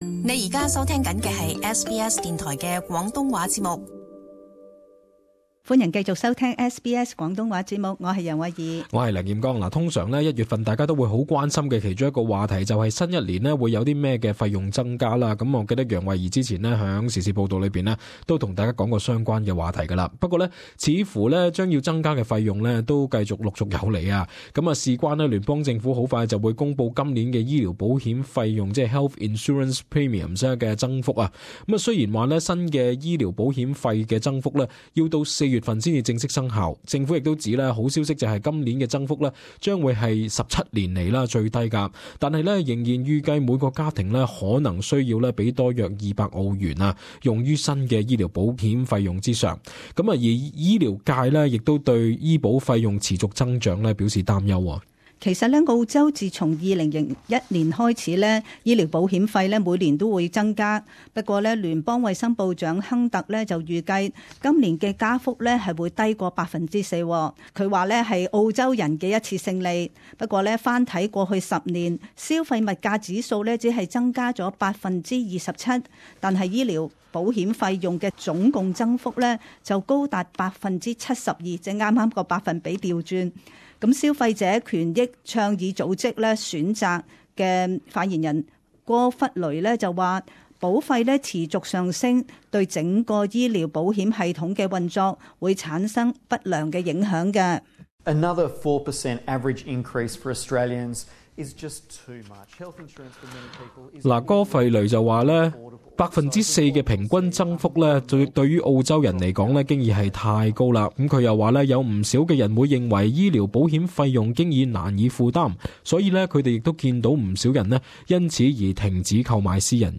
【時事報導】各界擔心澳洲家庭將難以負擔醫保費用增幅